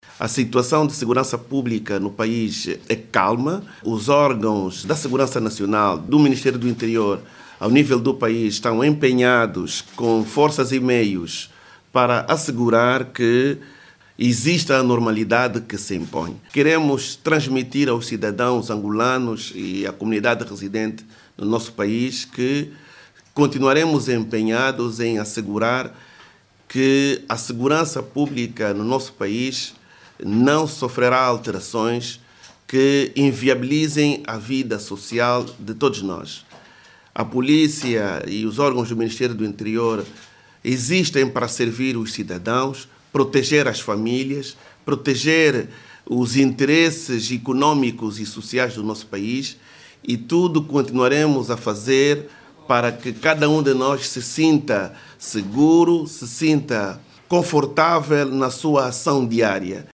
Manuel Homem assegurou, a saída sétima sessão do conselho de ministros, que na capital do país a situação regressou a normalidade.